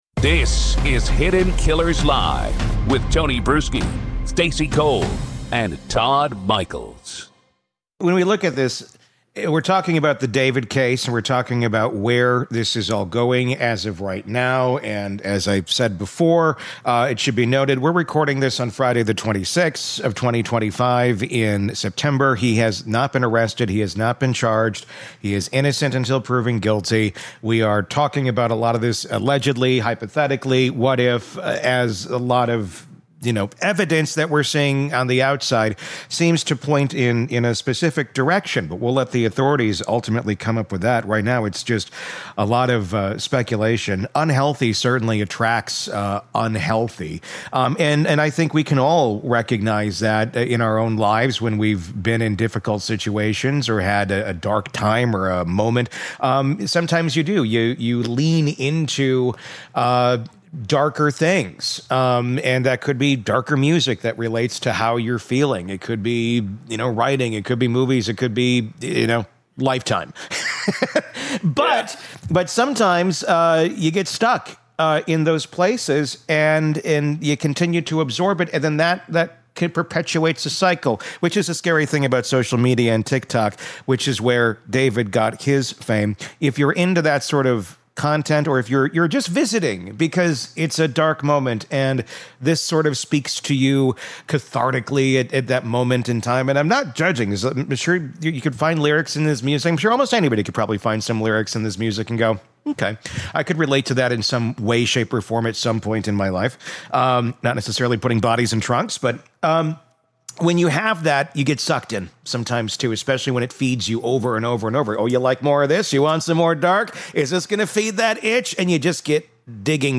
True Crime Today | Daily True Crime News & Interviews / D4VD Death Investigation: She Had His Tattoo.